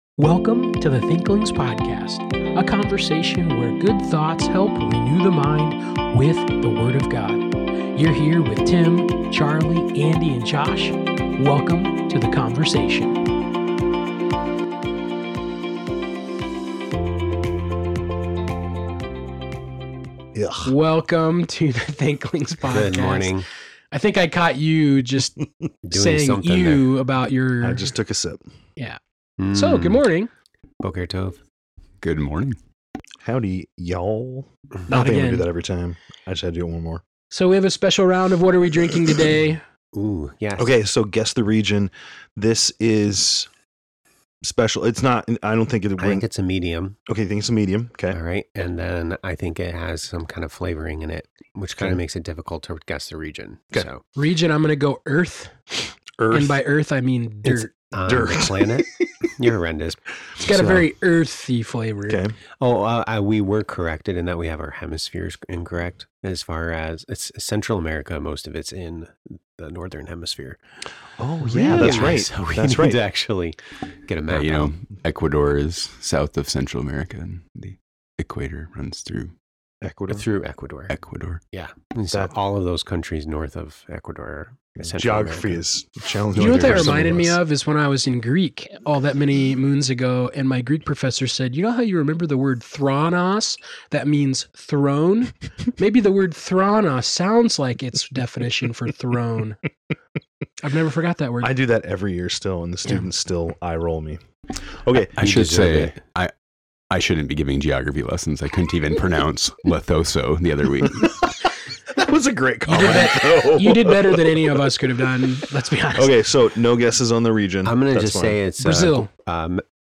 a thoughtful conversation about hand raising in worship. But before diving into that, we warm up with some fun-nonsense, coffee chatter, and the beloved segment: Books & Business ☕📚.